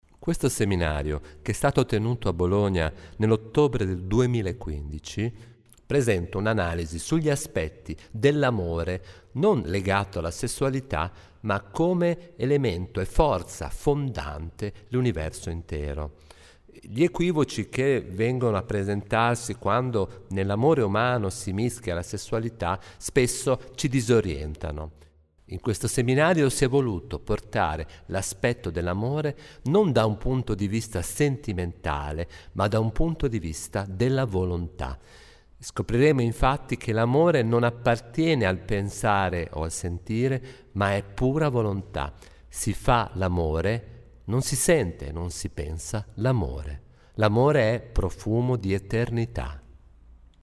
EroSacro, profumo di eternità 11-10-15 Bologna – 4 ore audio